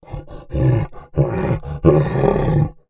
MONSTER_Exhausted_06_mono.wav